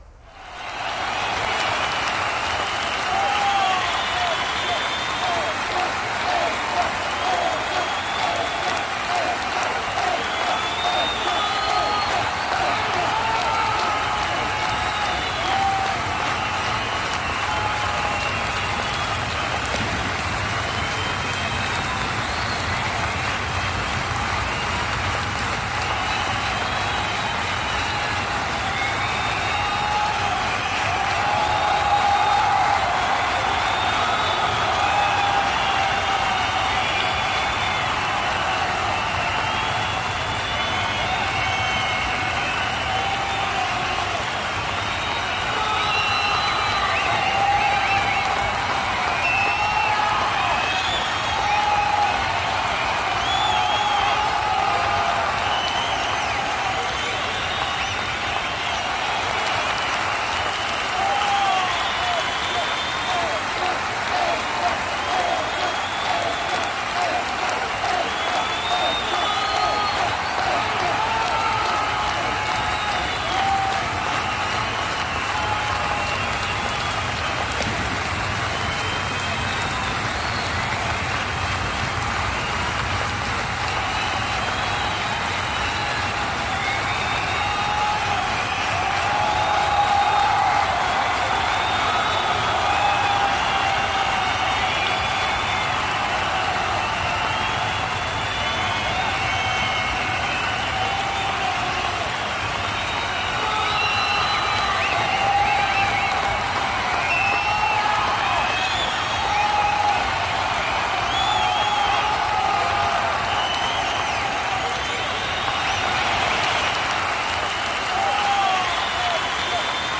دانلود آهنگ تشویق جمعیت در استادیوم 1 از افکت صوتی طبیعت و محیط
دانلود صدای تشویق جمعیت در استادیوم 1 از ساعد نیوز با لینک مستقیم و کیفیت بالا
برچسب: دانلود آهنگ های افکت صوتی طبیعت و محیط دانلود آلبوم صدای تشویق جمعیت در استادیوم و شادی گل از افکت صوتی طبیعت و محیط